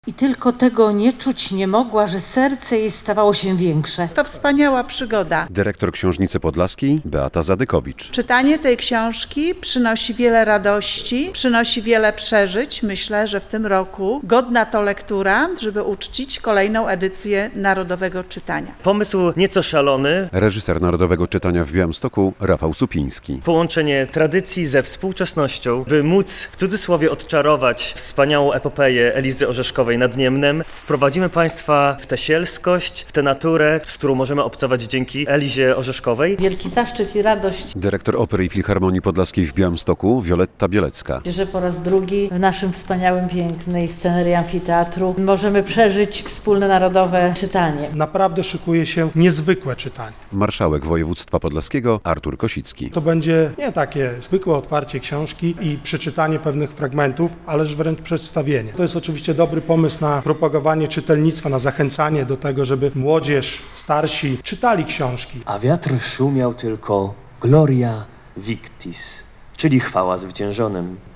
Narodowe Czytanie 2023 - relacja